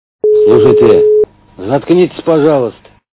При прослушивании Из к.ф. Джентельмены удачи - Слушайте... Заткнитесь, пожалуйста качество понижено и присутствуют гудки.